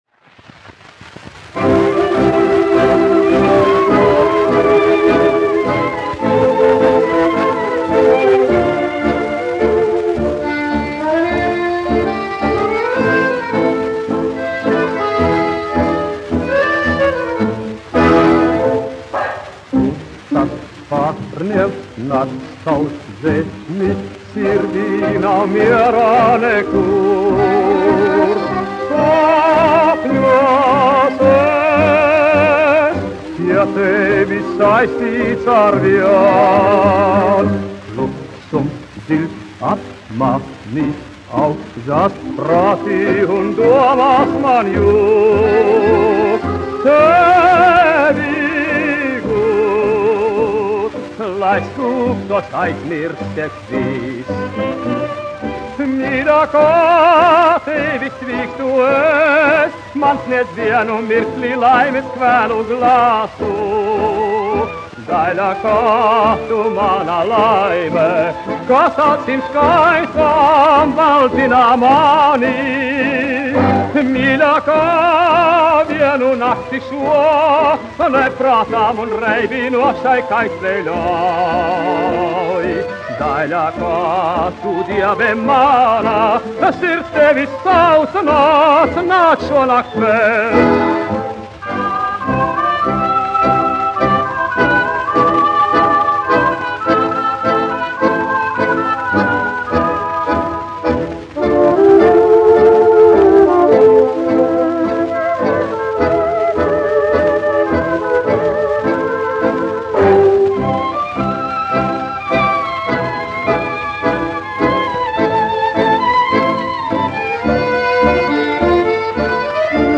1 skpl. : analogs, 78 apgr/min, mono ; 25 cm
Populārā mūzika -- Latvija
Latvijas vēsturiskie šellaka skaņuplašu ieraksti (Kolekcija)